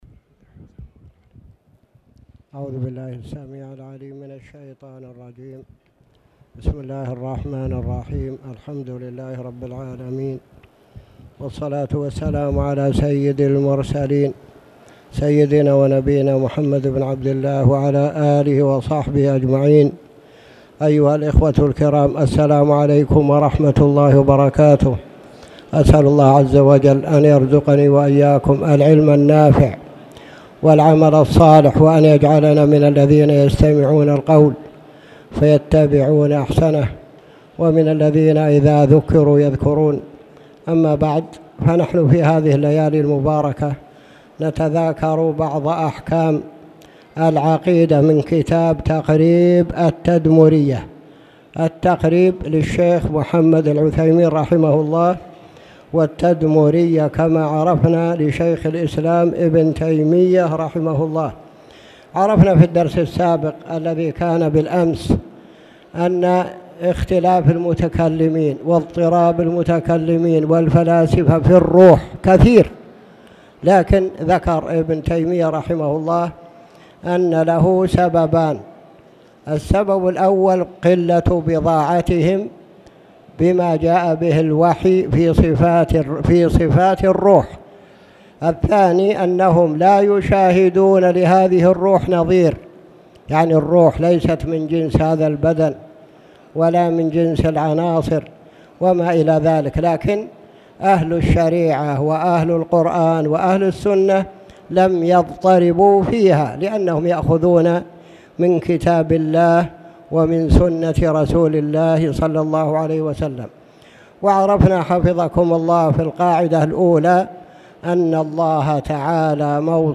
تاريخ النشر ٦ صفر ١٤٣٨ هـ المكان: المسجد الحرام الشيخ